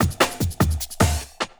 50 LOOP02 -L.wav